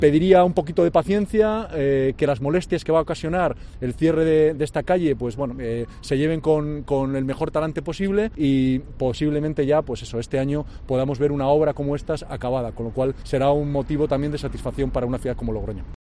El alcalde de Logroño, Pablo Hermoso de Mendoza, pide "paciencia"